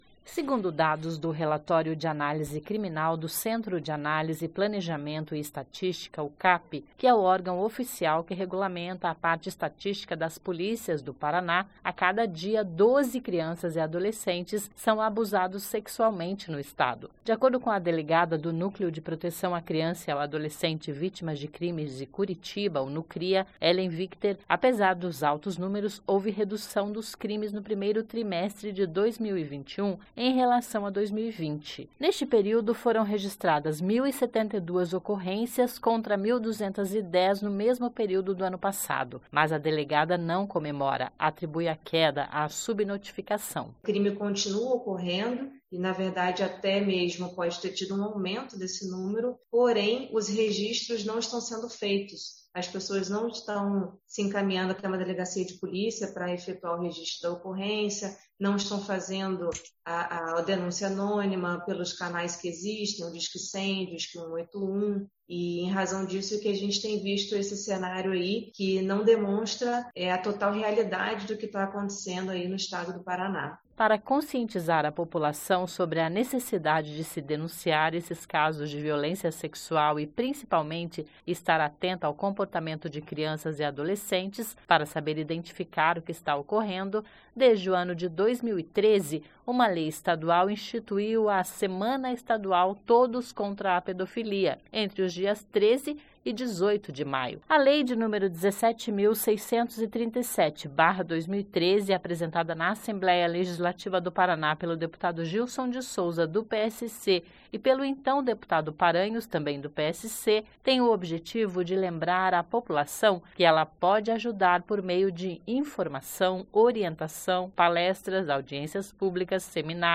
(Sobe som)
(Sonora)